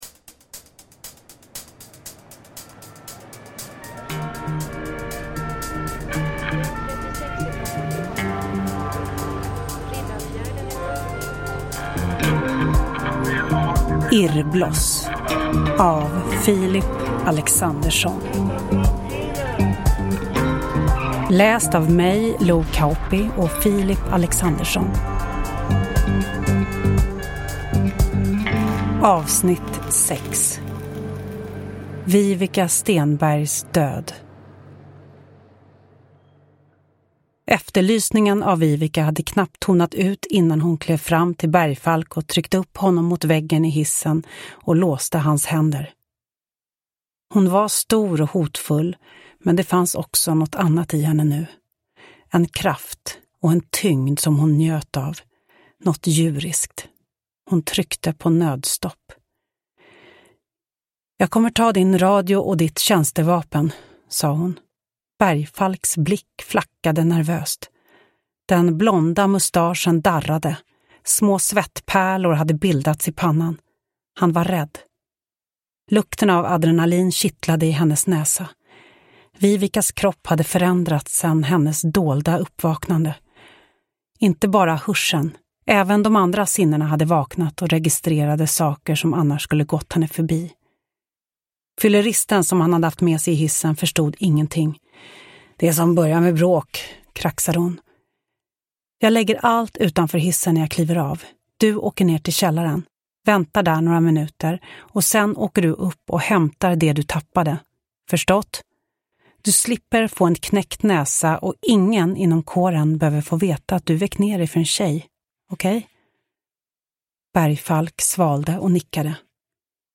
Hidden S1A6 Irrbloss : Viveca Stenbergs död – Ljudbok – Laddas ner